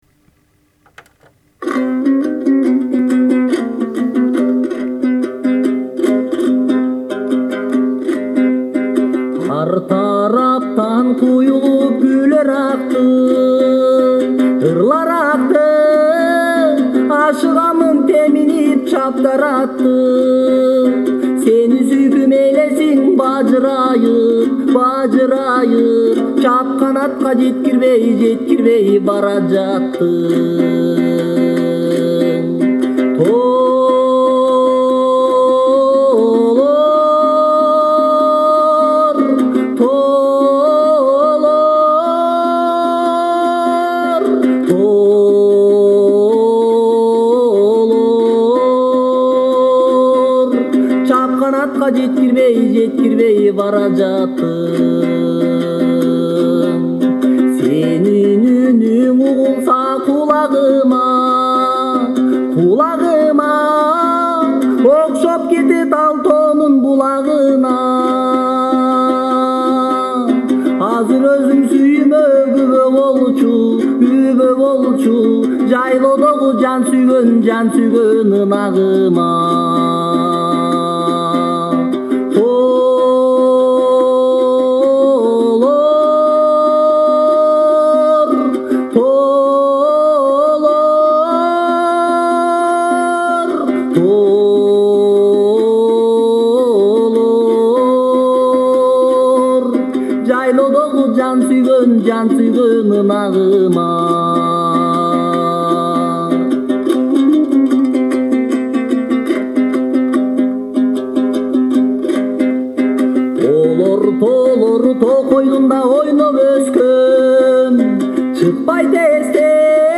キルギスの民族音楽・男声　（ＣＤ）
kyrgyz-male.MP3